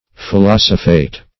Search Result for " philosophate" : The Collaborative International Dictionary of English v.0.48: Philosophate \Phi*los"o*phate\, v. i. [L. philosophatus, p. p. of philosophari to philosophize.]